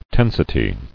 [ten·si·ty]